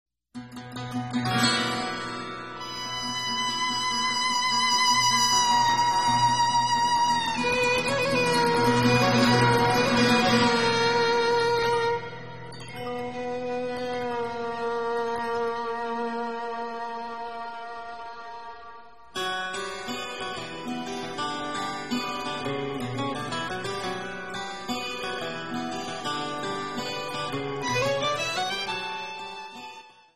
Acoustic
Americana
Bluegrass
Folk & Traditional
Instrumental
Jazz